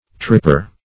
Tripper \Trip"per\, n.